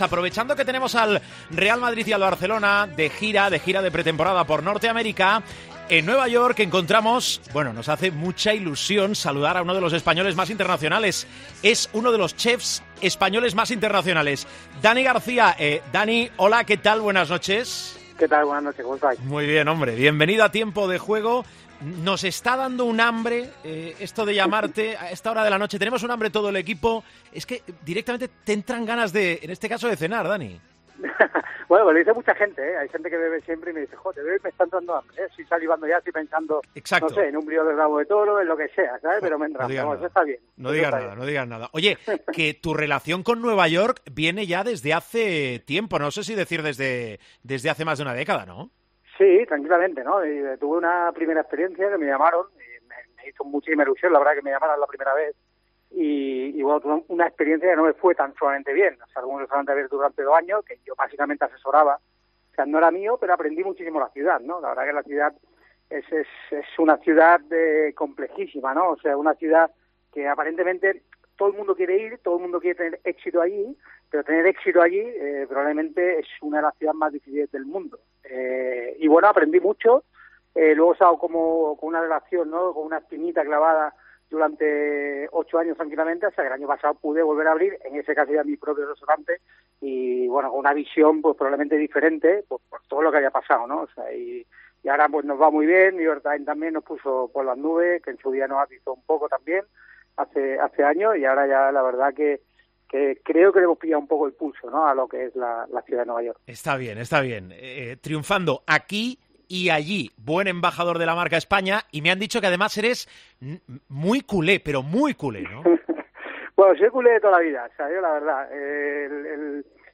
El chef con tres estrellas Michelín habla en el tramo final de Tiempo de Juego.